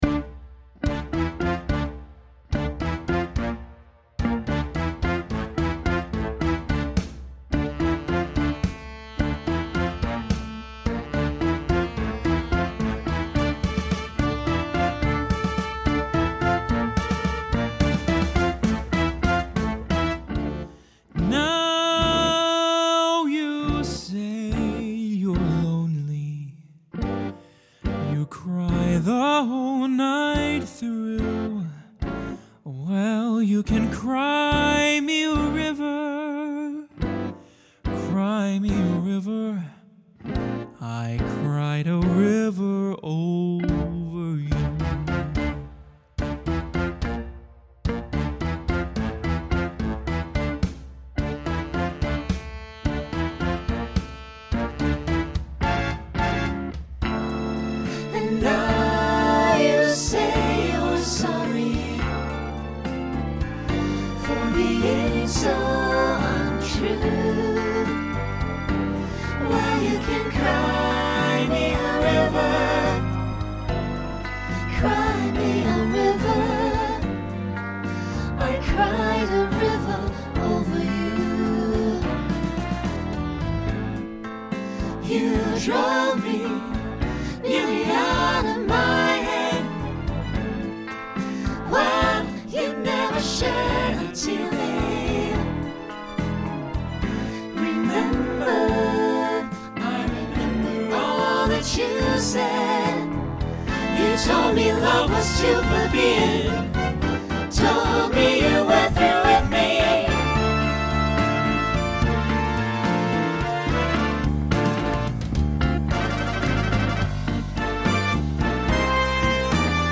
Voicing SATB Instrumental combo Genre Swing/Jazz
Mid-tempo